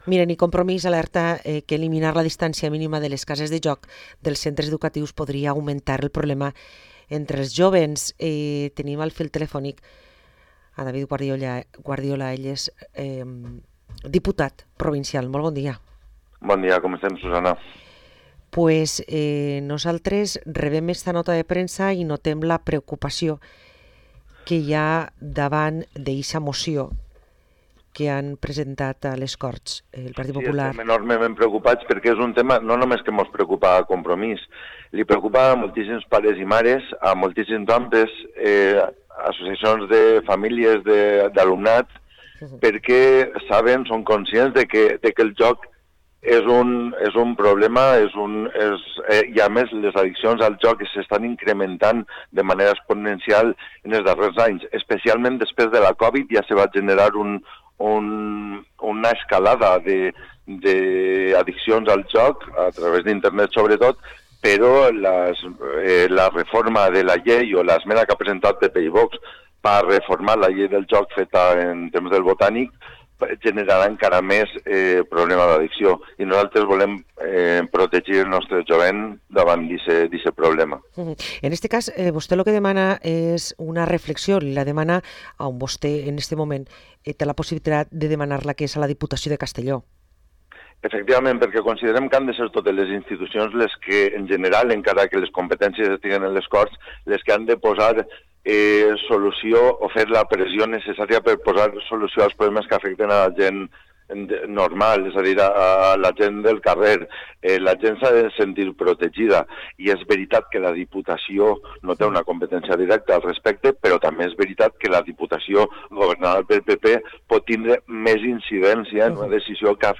Parlem amb David Guardiola, Diputat de Compromís